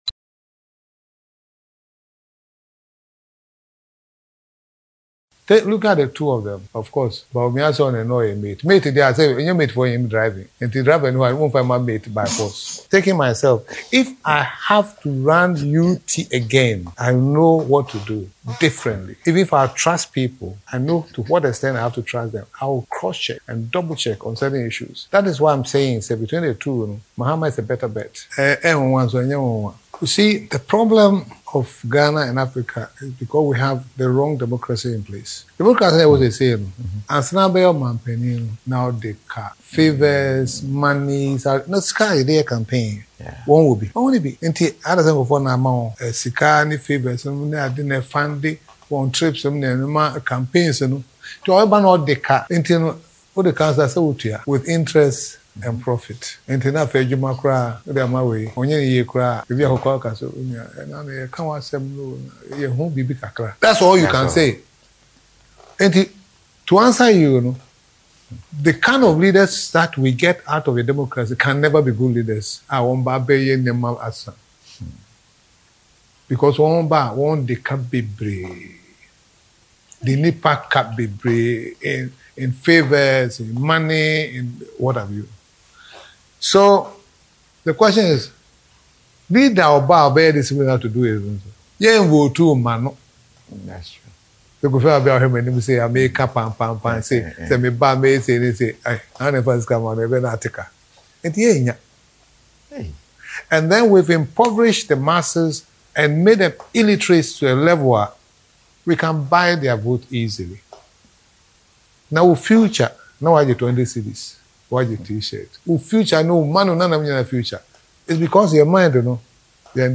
Speaking in an interview on KOFI TV, the business mogul and founder of defunct UT Bank and UT Holdings explained his current stance and cited his observation of the governing NPP, in the last seven years in office, as the basis for his reason.